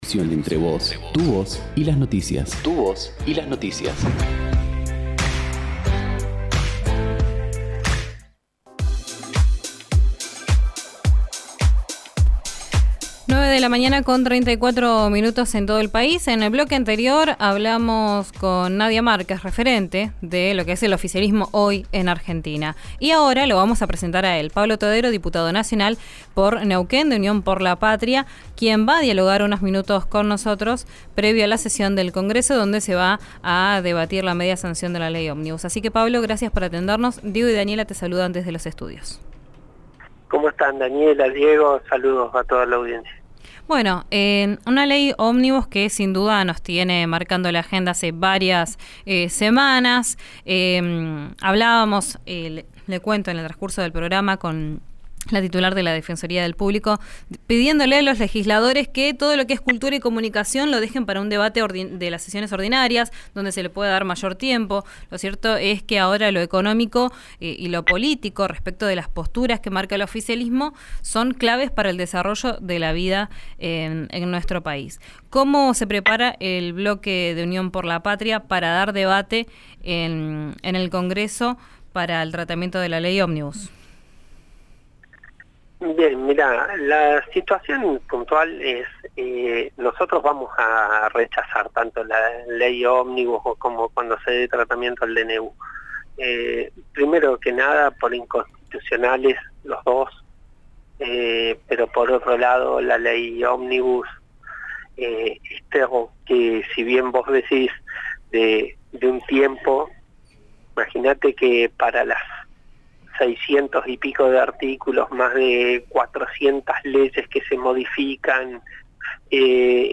El diputado nacional por Neuquén que integra el bloque de Unión por la Patria sostuvo que, por más negociación que haya, si se le delegan facultades al Ejecutivo va a dar marcha atrás con los acuerdos por decreto. Escuchá la entrevista en RÍO NEGRO RADIO.